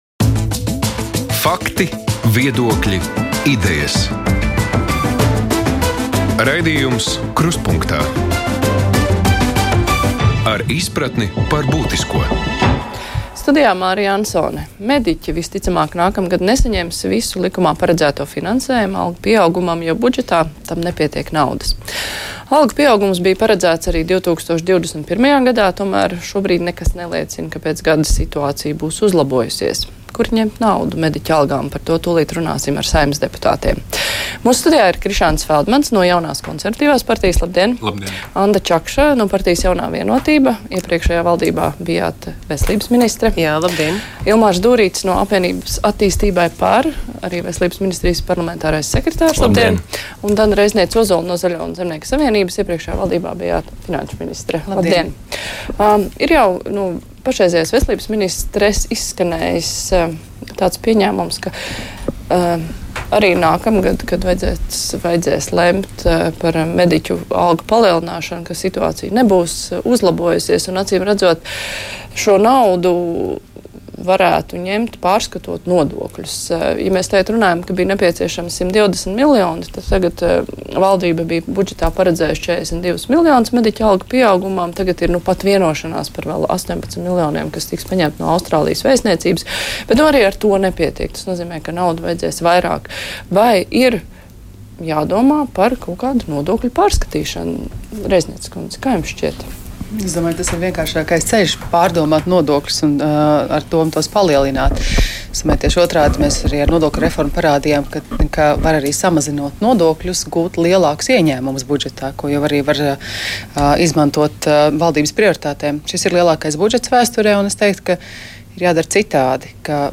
Krustpunktā diskusija: Kur rast naudu mediķu algu palielināšanai?